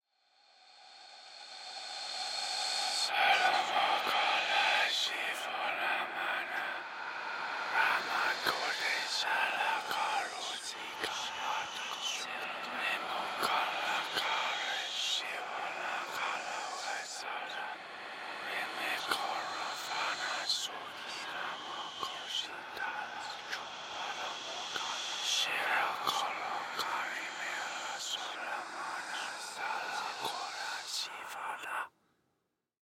Звук призрака, читающего заклинание в темном углу